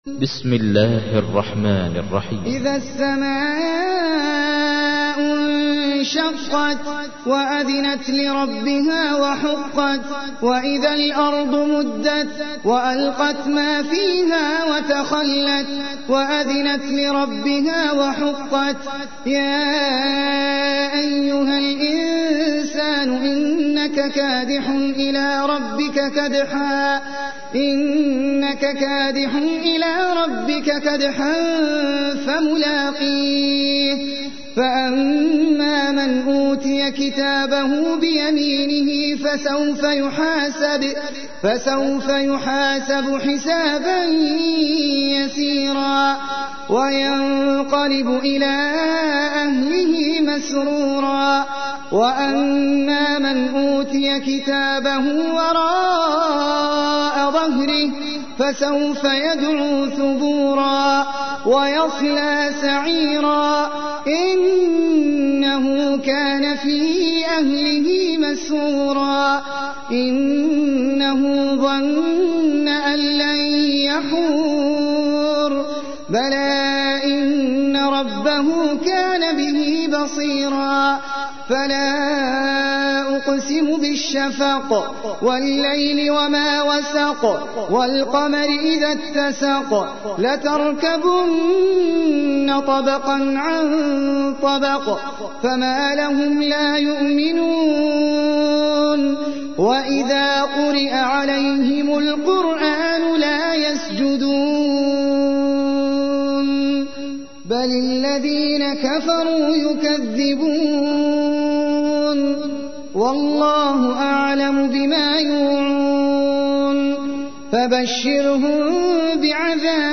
تحميل : 84. سورة الانشقاق / القارئ احمد العجمي / القرآن الكريم / موقع يا حسين